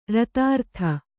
♪ ratārta